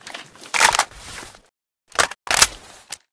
aks74u_reload.ogg